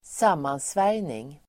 Uttal: [²s'am:ansvär:jning]